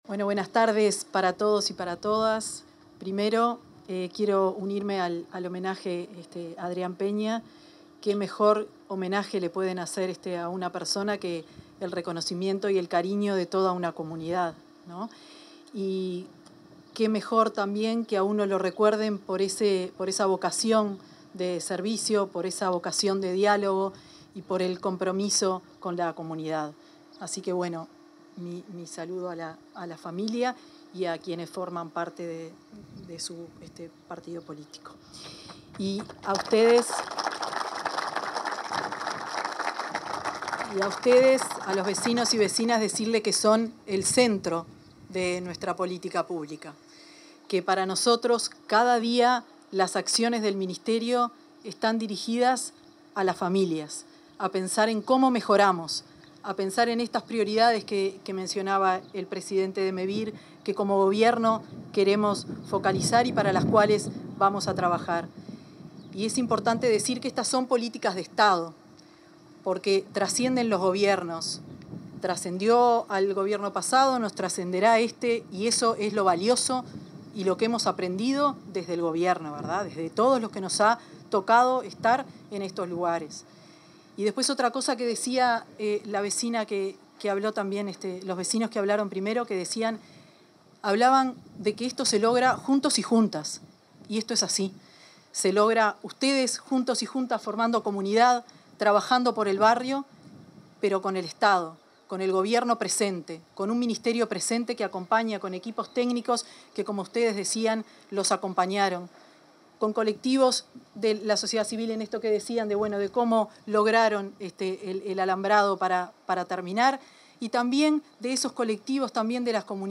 Palabras de la ministra de Vivienda, Tamara Paseyro 28/11/2025 Compartir Facebook X Copiar enlace WhatsApp LinkedIn La titular del Ministerio de Vivienda y Ordenamiento Territorial, Tamara Paseyro, hizo uso de la palabra en la entrega de 33 viviendas nuevas en la ciudad de San Bautista, departamento de Canelones.